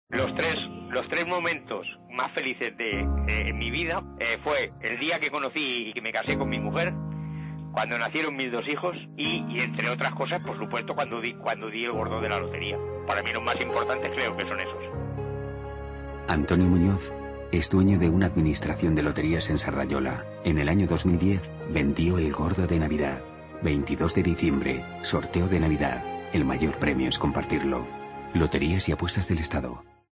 Escucha una de las cuñas del sorteo de Navidad de Loterías y Apuestas del Estado